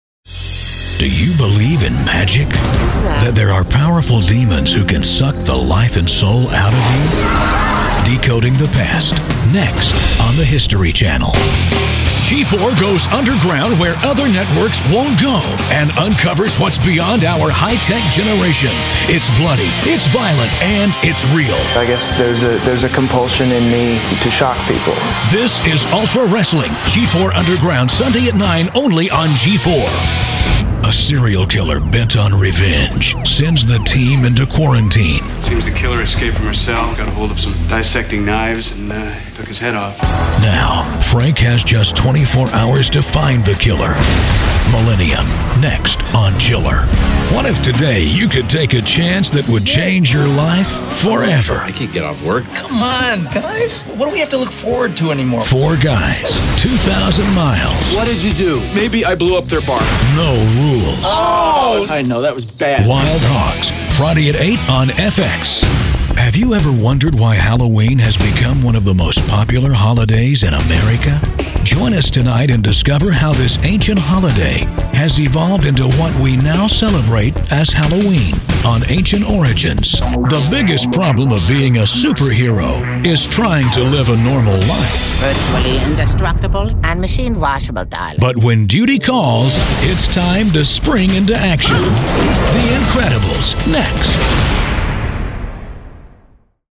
Never any Artificial Voices used, unlike other sites.
Adult (30-50)